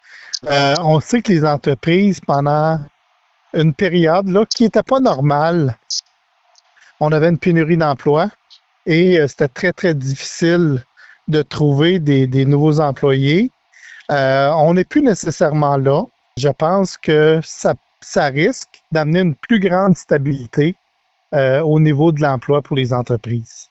Le député de Nicolet-Bécancour a dit quelques mots sur ce qu’il a remarqué dernièrement.